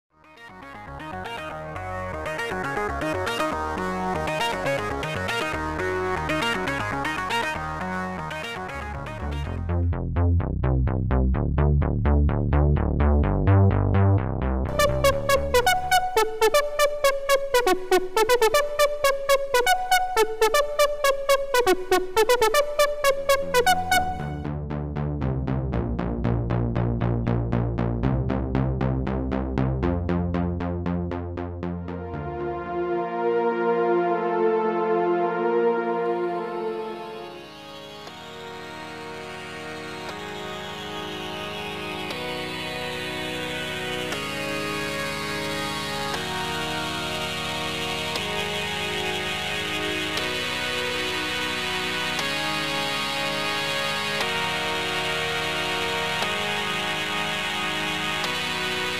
Presets inspired by analogic synth sounds